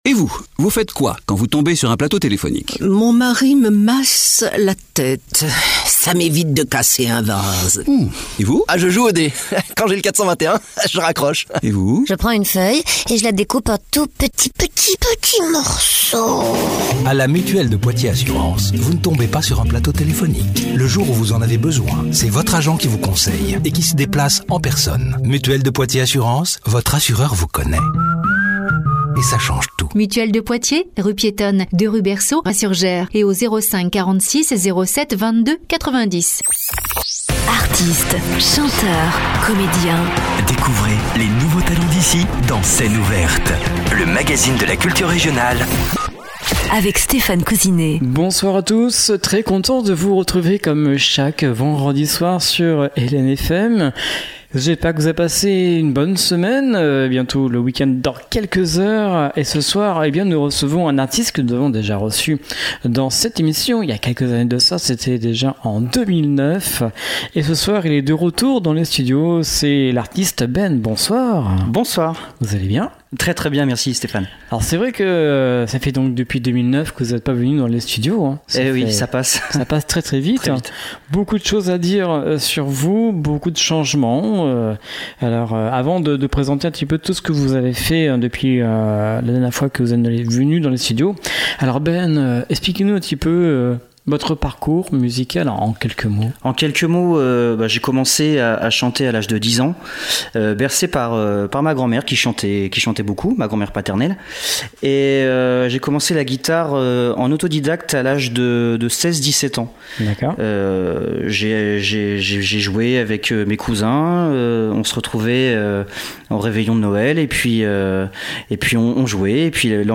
Scène ouverte avec le chanteur
Une voix écorchée